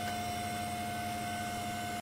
computer_mid2.ogg